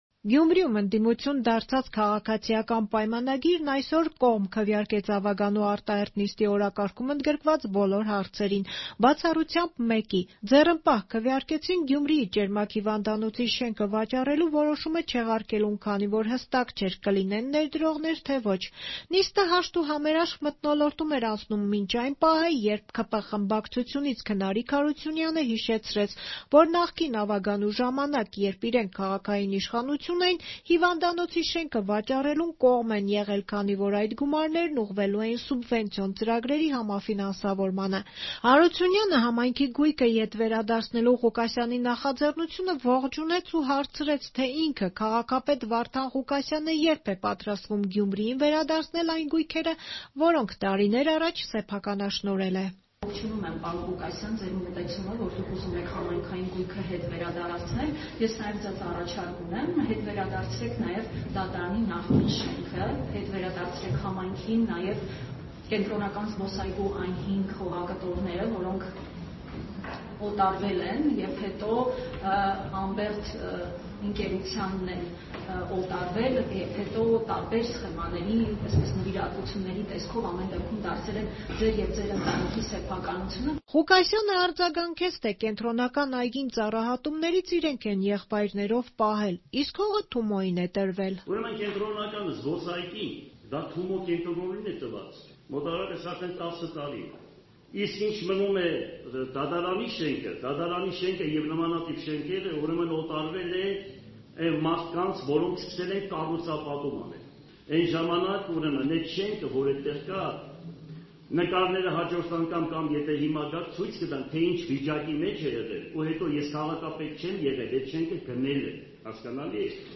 Գյումրիի ավագանու նիստը հաշտ ու համերաշխ էր անցնում մինչև ՔՊ-ականի՝ Ղուկասյանին ուղղած հարցը
Ռեպորտաժներ